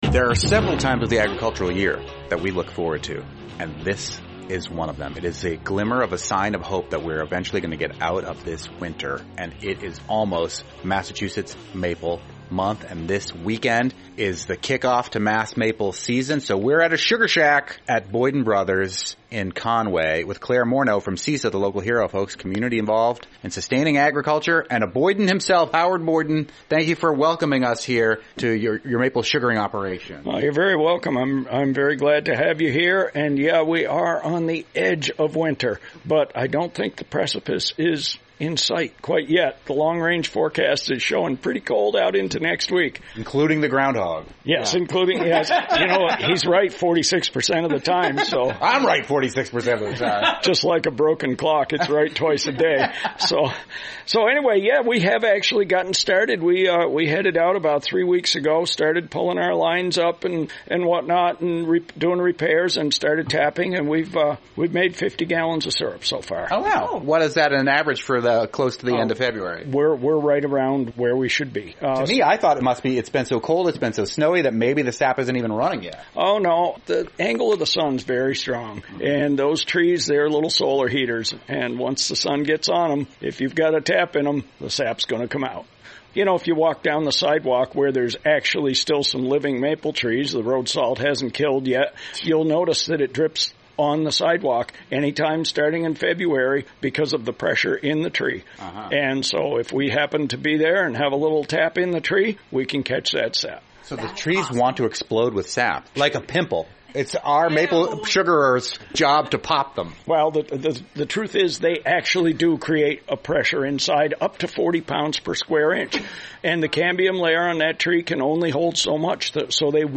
NEPM "Fabulous 413" interviews